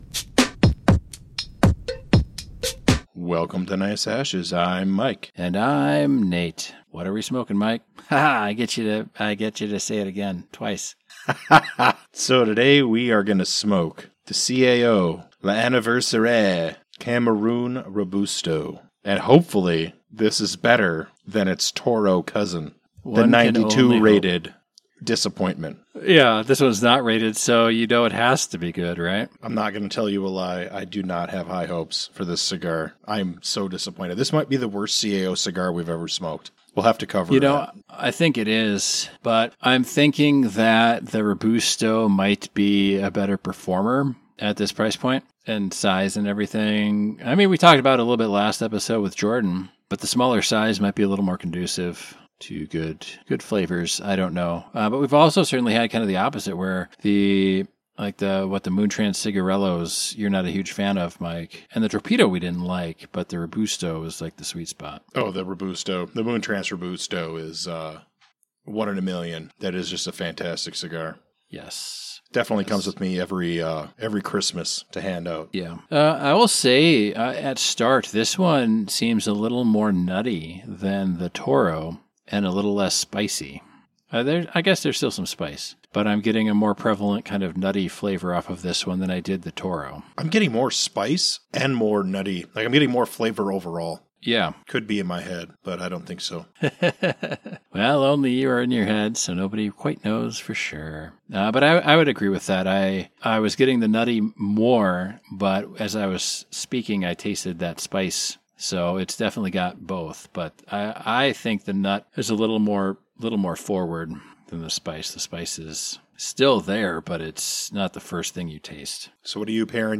As they smoke, the duo shifts gears to explore the dynamics of the modern job market, particularly how companies are prioritizing diversity, equity, and inclusion (DEI) in hiring practices. They tackle controversial topics like "reverse discrimination" and the implications of AI-driven recruitment processes, sharing personal anecdotes and insights from their own job searches. Tune in for a lively debate on the balance between qualifications and diversity in hiring.